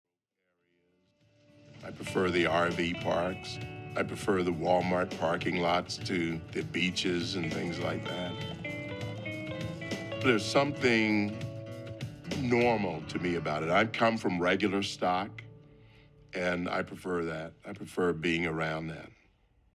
Listen to Thomas speak, from the documentary “Created Equal.”